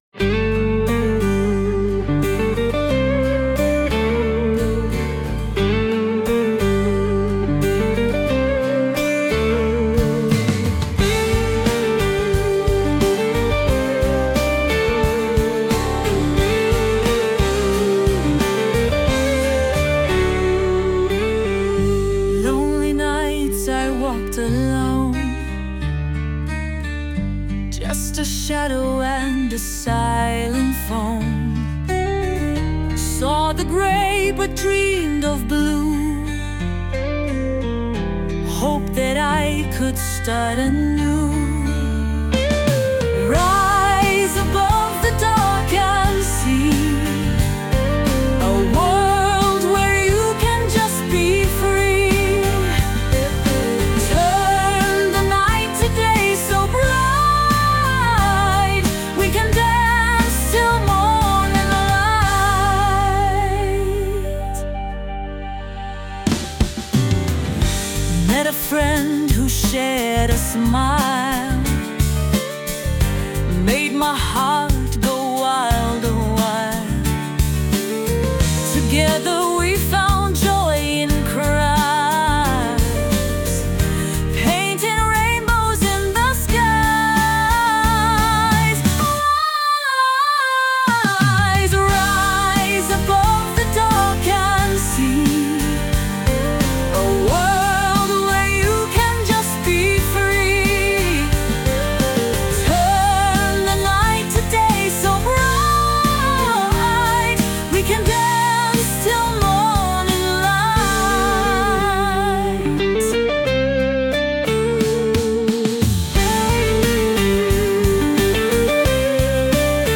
With Vocals / 歌あり
A dramatic ballad featuring emotional female vocals.
最初は静かで儚げな雰囲気ですが、曲が進むにつれて、内側から湧き上がるような「力強さ」が増していきます。